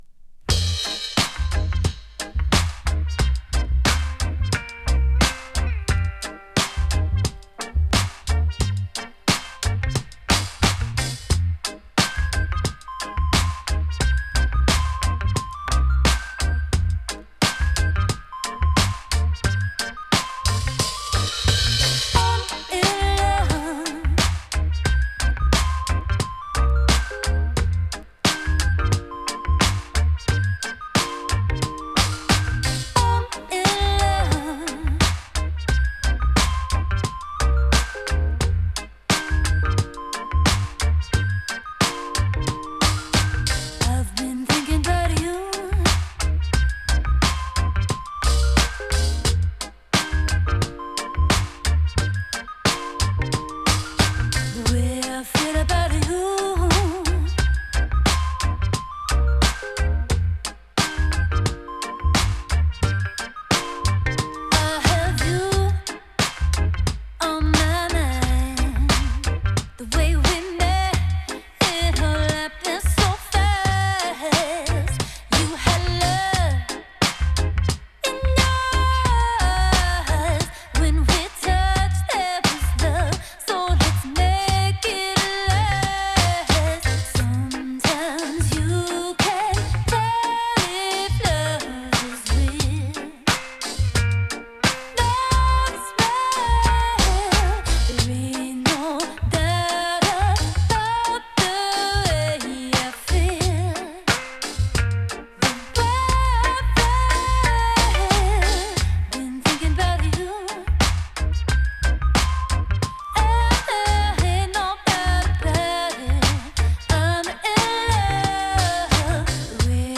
シティポップ、メロウ・ファンクが見事に融合された唯一無二のサウンドここにあり。